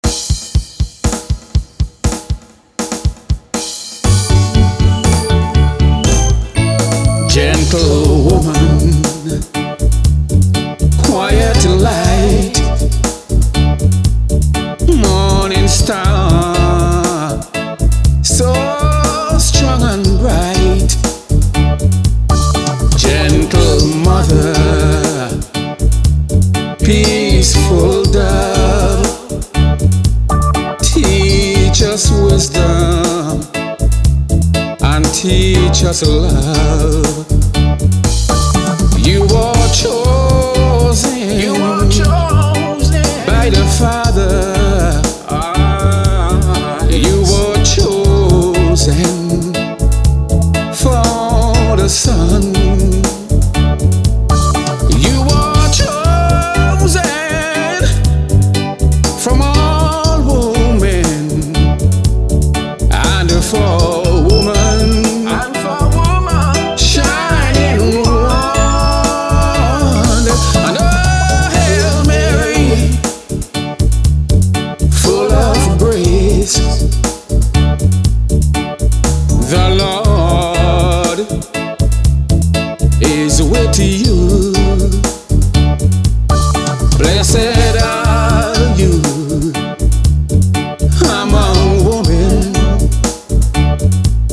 Gospel (calypso gospel)